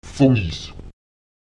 Grundsätzlich wird in yorlakesischen Wörtern die erste Silbe betont: